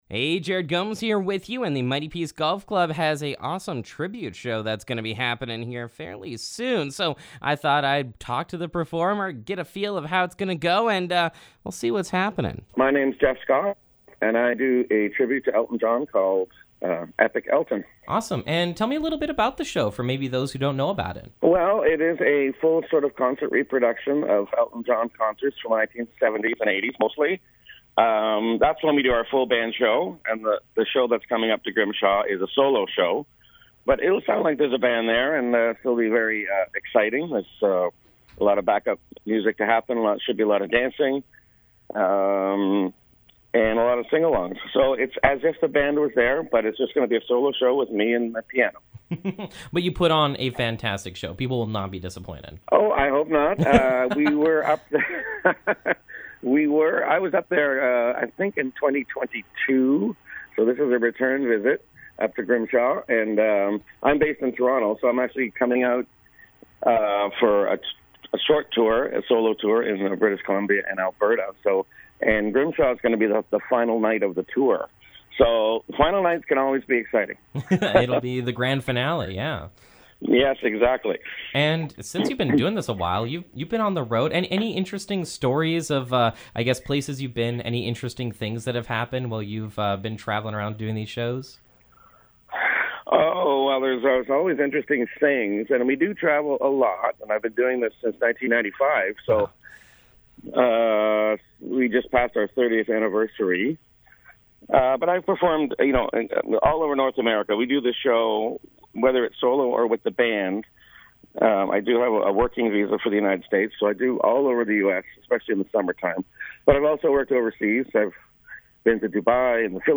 Epic Elton Interview
elton-john-tribute-interview-edit.mp3